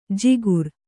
♪ jigur